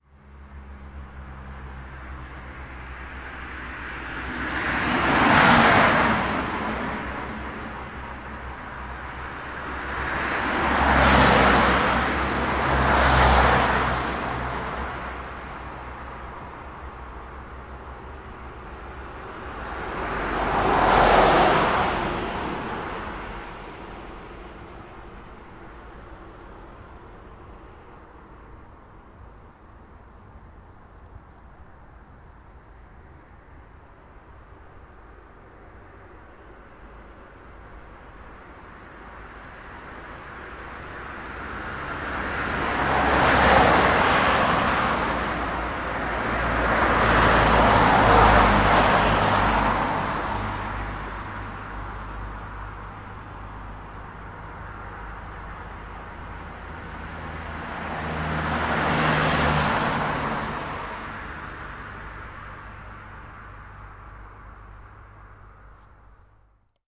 氛围 " 多车快速通行证
描述：只有一些车在晚上11点开车经过周六晚上。
Tag: 变焦过去 螺栓过去 车驱动用的拉链式过去 汽车通 现场记录 汽车快速通